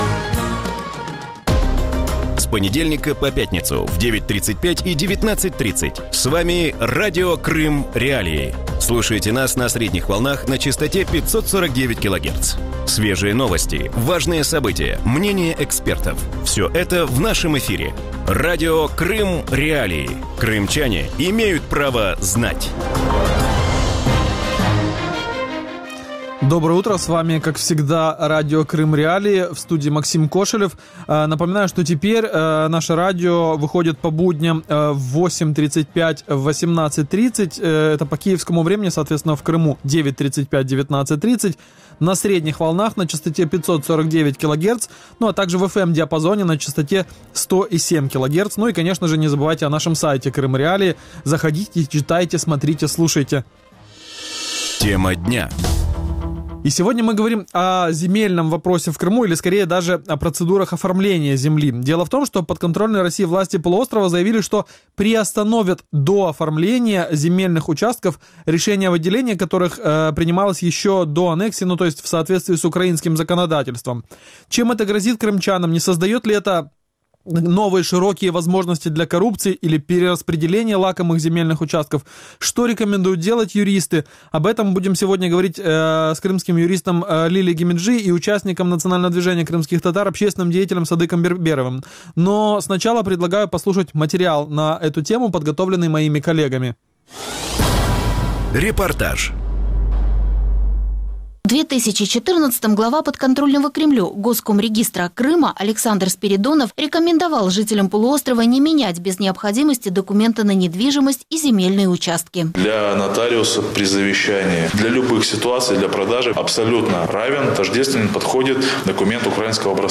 У ранковому ефірі Радіо Крим.Реалії говорять про земельне питання в Криму. Підконтрольна Росії влада півострова заявили про намір призупинити дооформлення земельних ділянок, виданих до анексії.